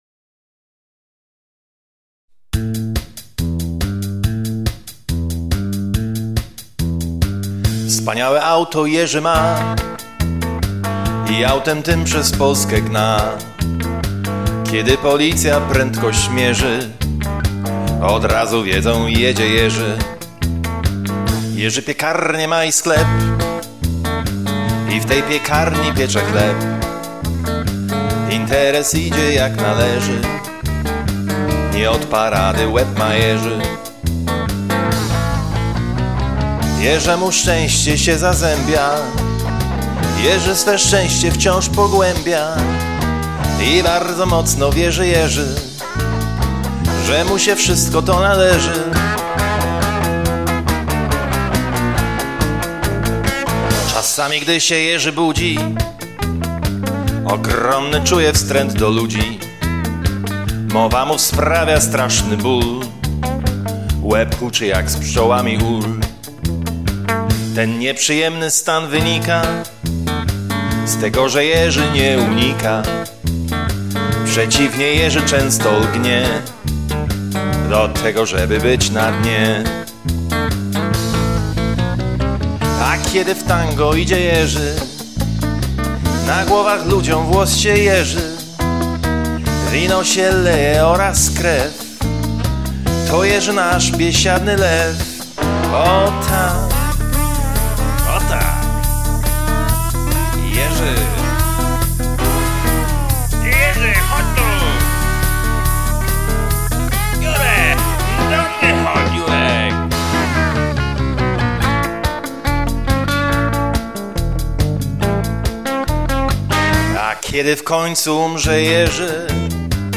Piosenka